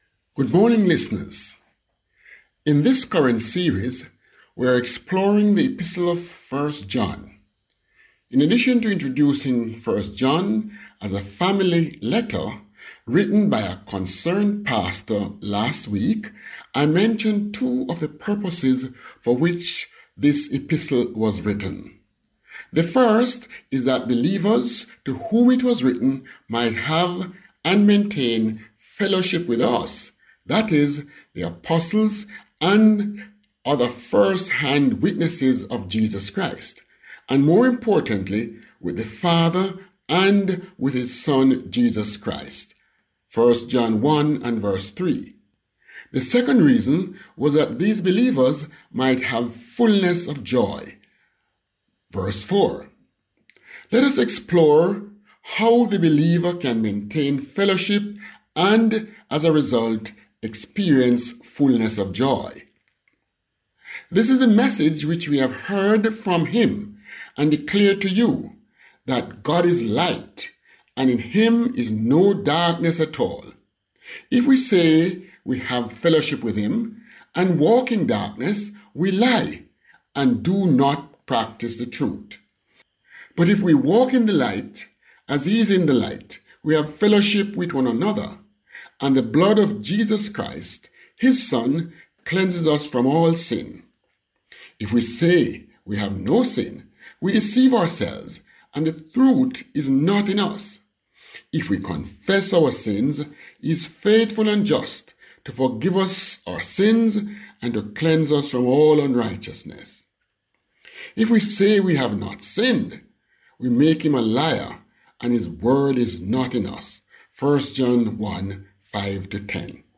Grace Hour Broadcast